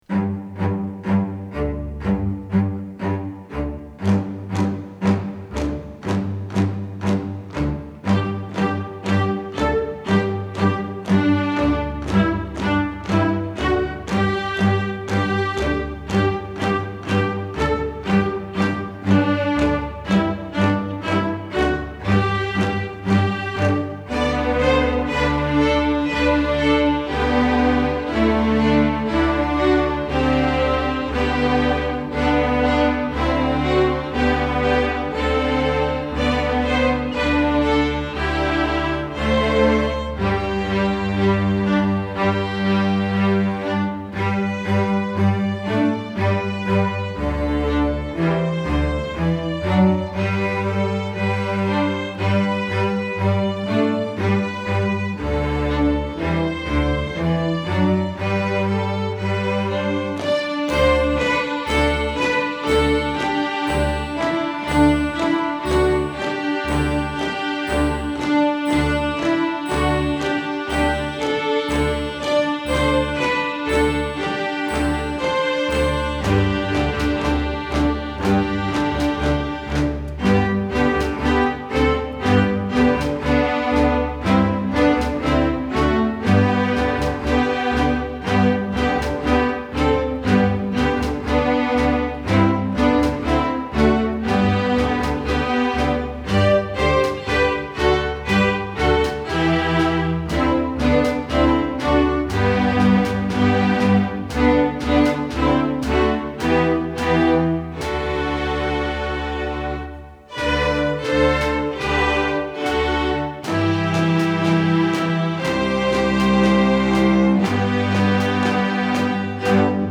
Instrumental Orchestra String Orchestra
Arranger
String Orchestra